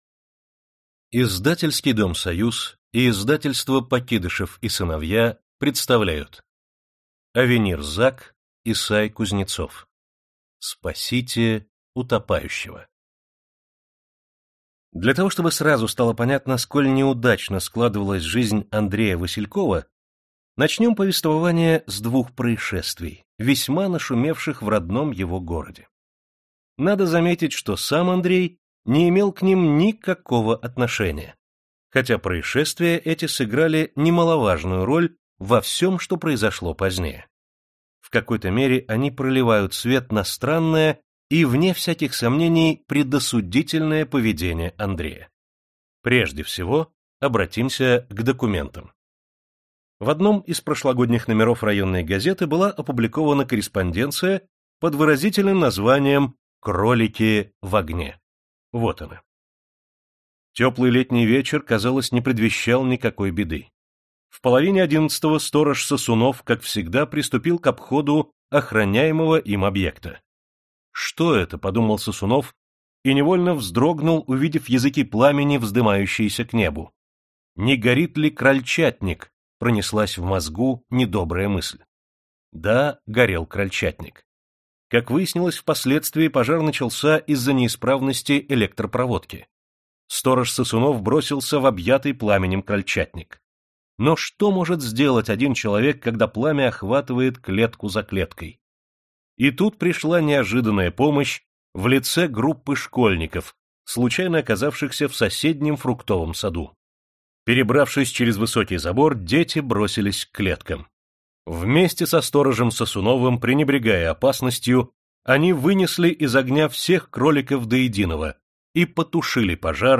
Aудиокнига Спасите утопающего